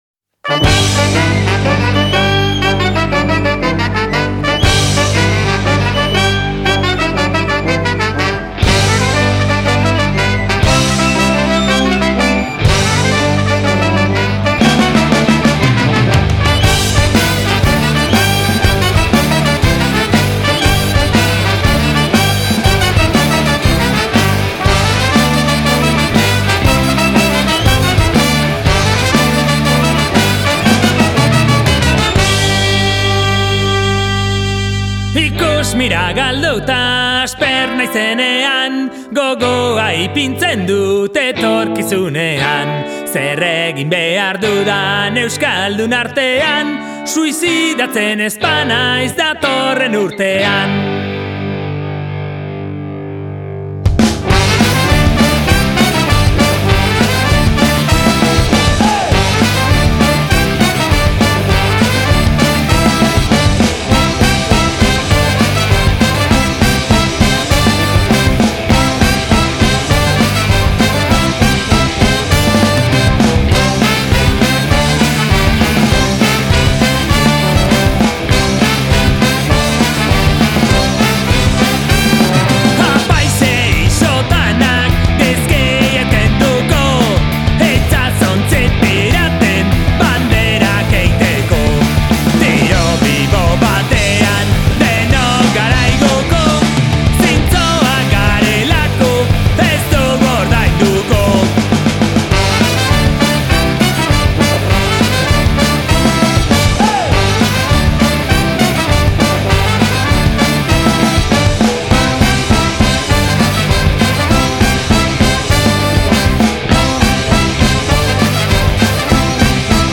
saxo
voz principal